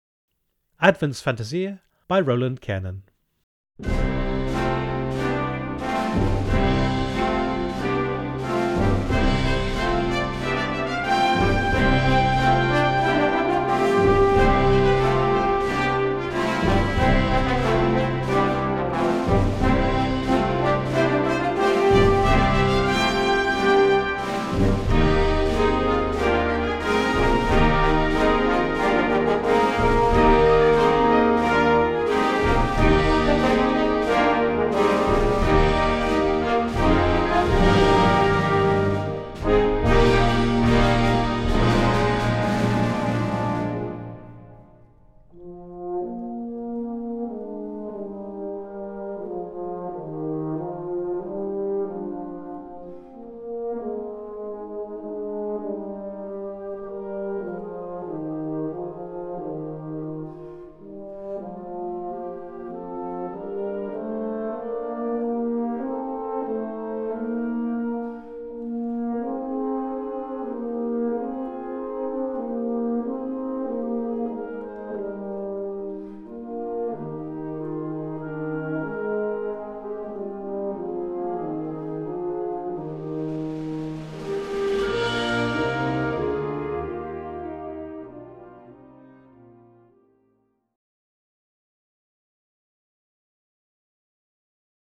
Concert Band ou Harmonie ou Fanfare ou Brass Band